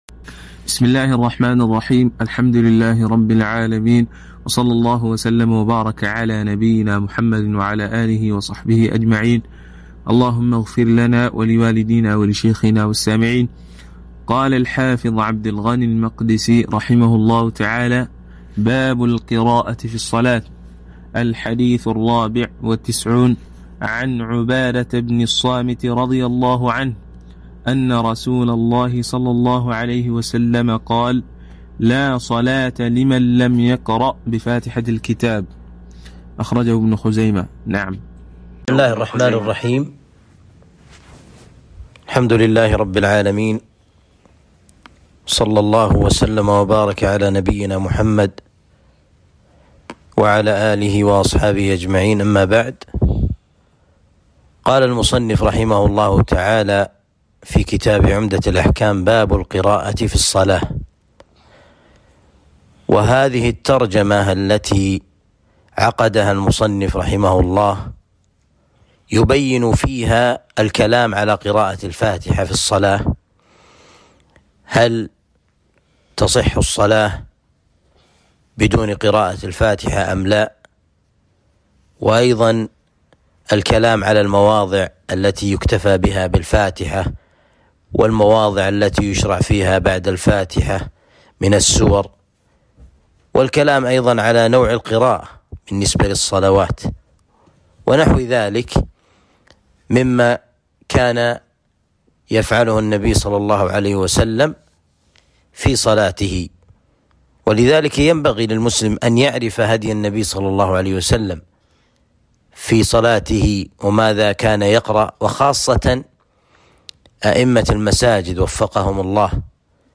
شرح عمدة الأحكام الدرس {٢٥} باب القراءة في الصلاة